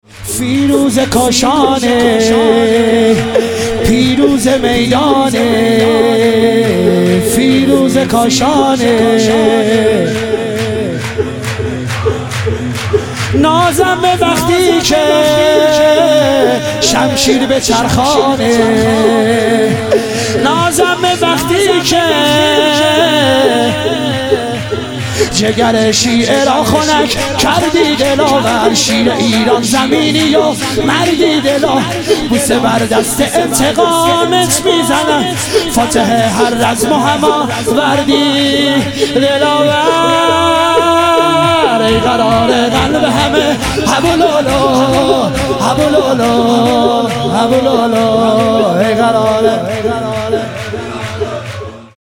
عید سعید غدیر خم - شور